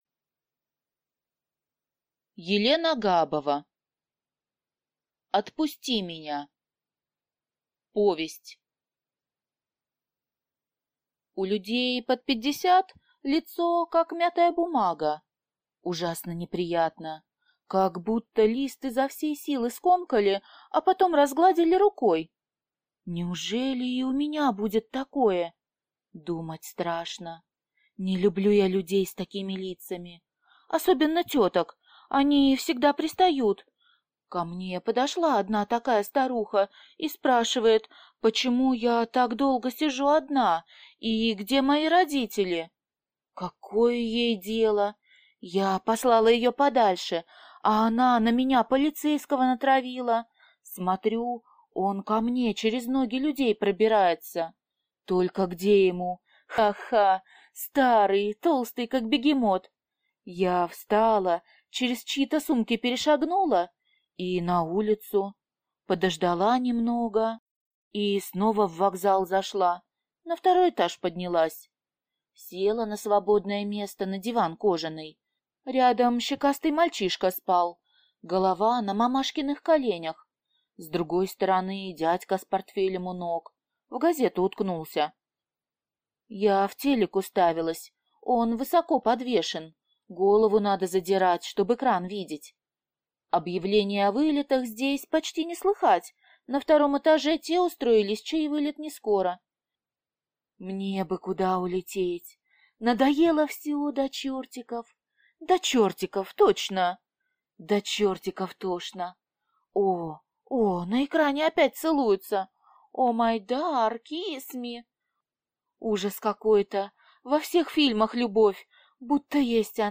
Аудиокнига Отпусти меня | Библиотека аудиокниг